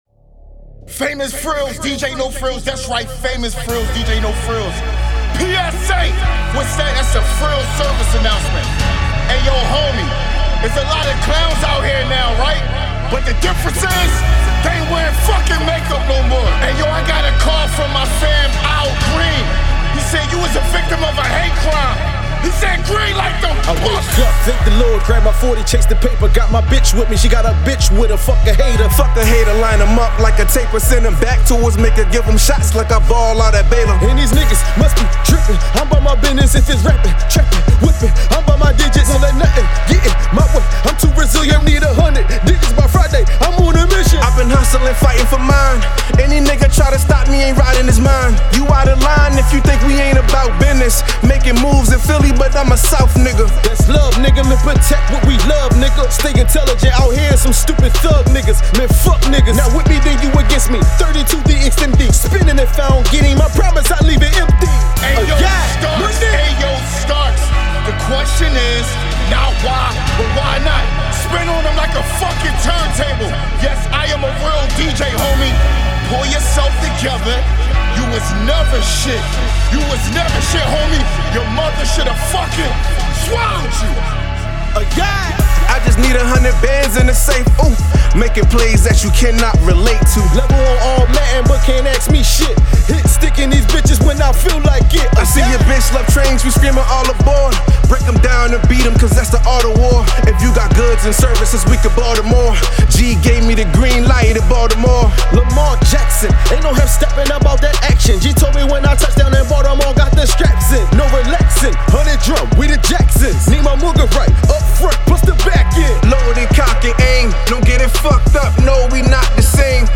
Hiphop
is a highly energetic track